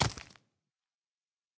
minecraft / sounds / mob / zombie / step5.ogg
step5.ogg